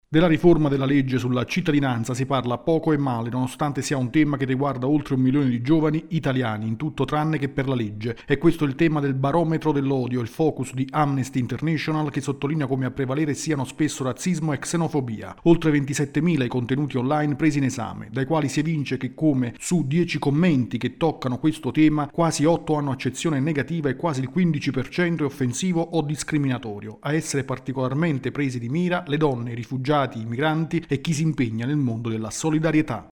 La quinta edizione del “Barometro dell’odio” di Amnesty International è dedicato alla riforma della legge della cittadinanza. Il servizio